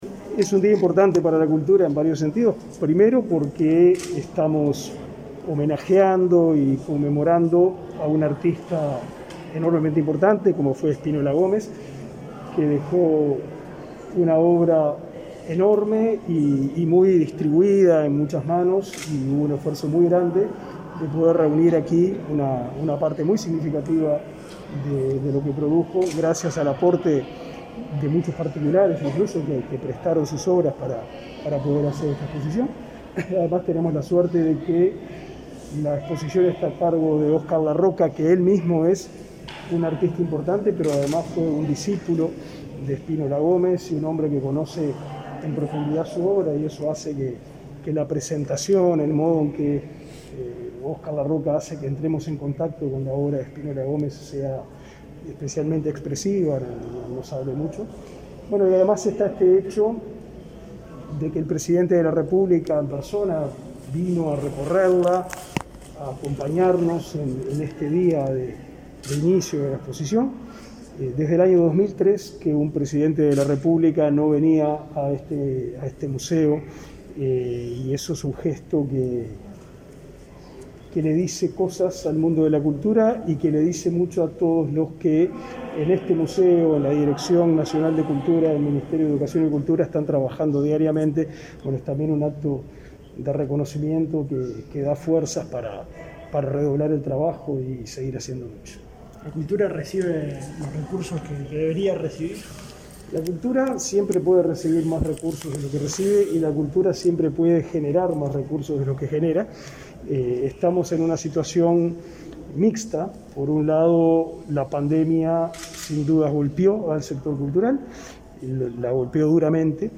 Declaraciones a la prensa del ministro de Educación y Cultura, Pablo da Silveira
Al finalizar el recorrido, el jerarca brindó declaraciones a la prensa.